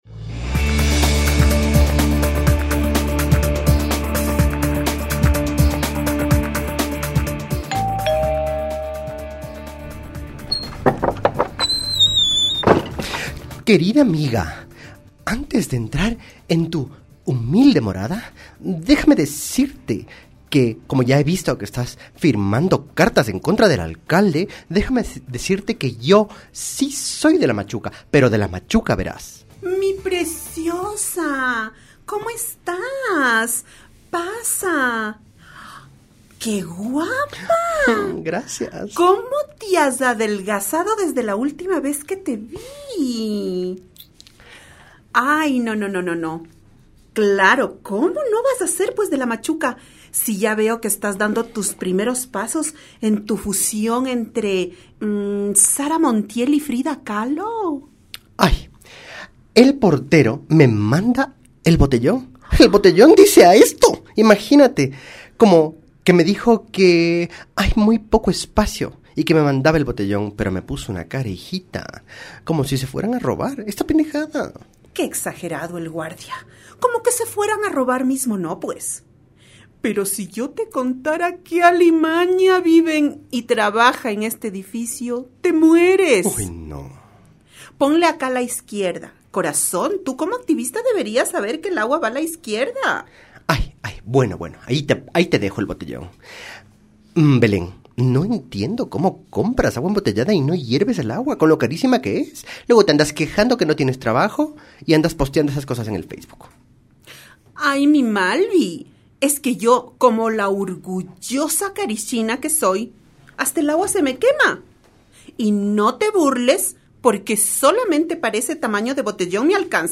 Cuando se dice que habla de ti, se refieren a todo este componente que nos importa de lo socio-político-cultural, pero desde una mirada dramatúrgica como es la farsa. Además, los entrevistados nos brindaron sus reflexiones sobre la situación del mundo artístico en relación a las coyunturas que se viven en la actualidad.